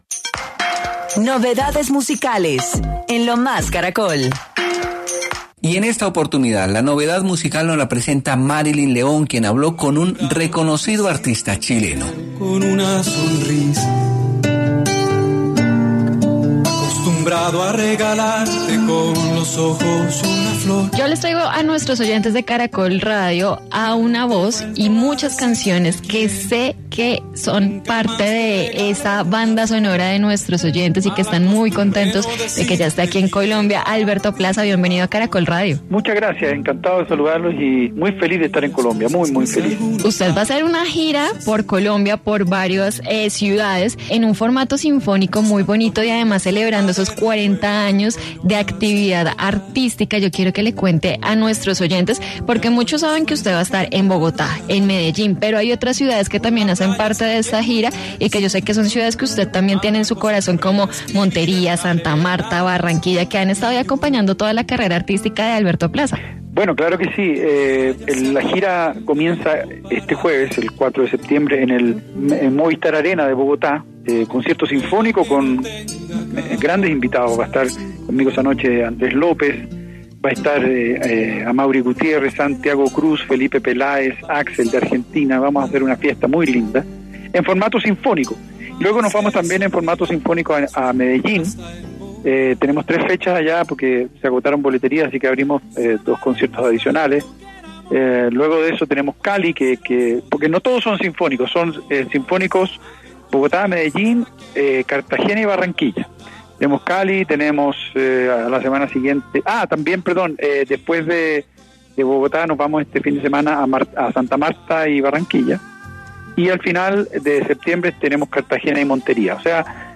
Alberto Plaza, estuvo en Lo Más Caracol contando detalles de sus conciertos y de su trayectoria musical, reveló que: “Esta es una gira muy linda, muy llena de amor, estoy muy feliz de estar en Colombia, los conciertos son magia pura”